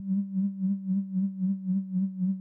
movement.wav